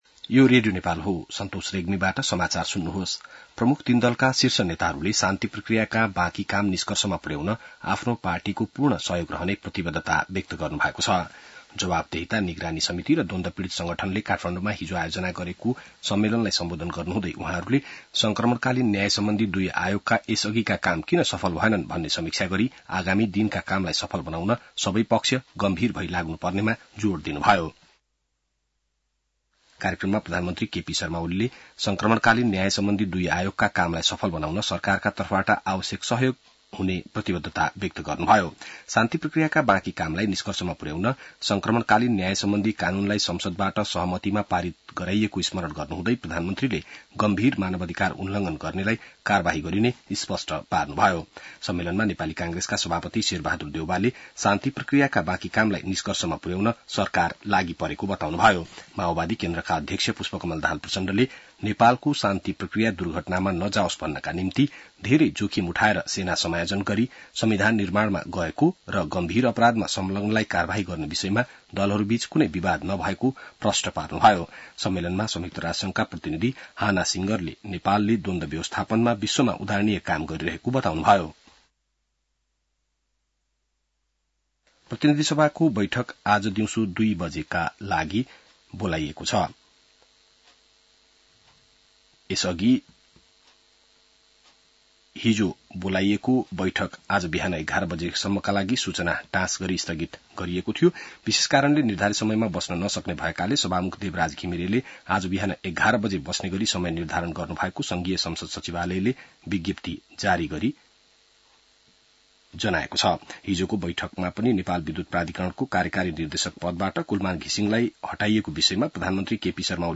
बिहान ६ बजेको नेपाली समाचार : १५ चैत , २०८१